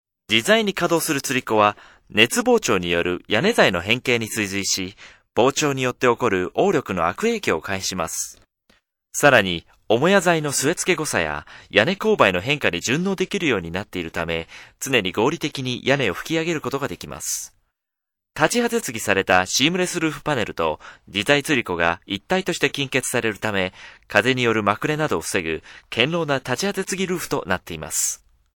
japanischer Sprecher für Werbung, internet, podcast, e-learning, Dokumentationen uva. Japanes voice over talent
Sprechprobe: Werbung (Muttersprache):
japanese male voice over artist